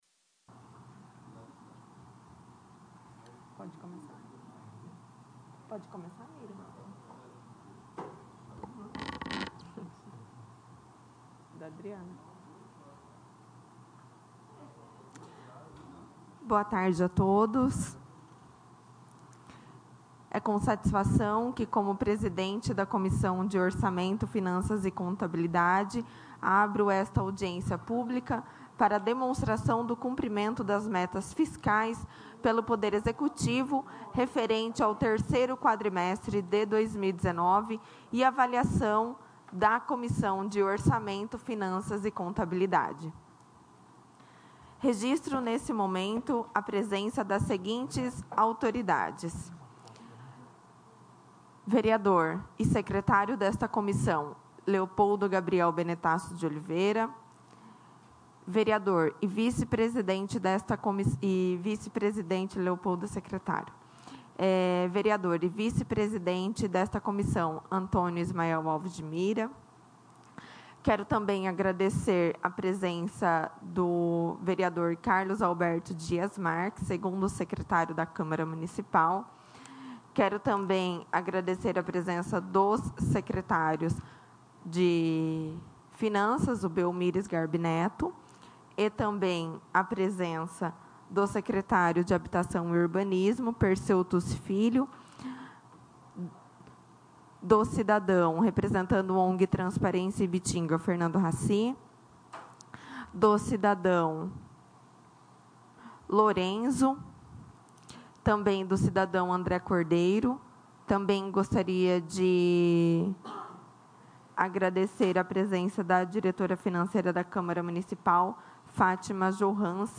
Audiência Pública de 27/02/2020